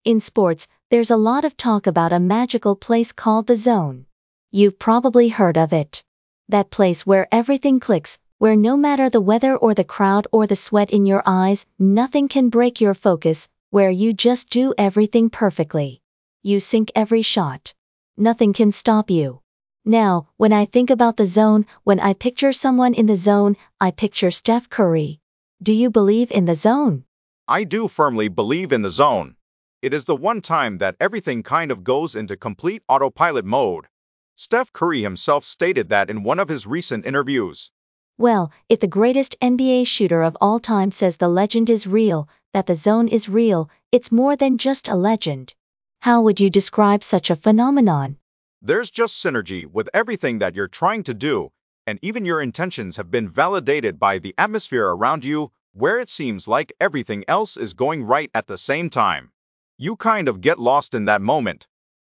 re-tell-lecture-interview.mp3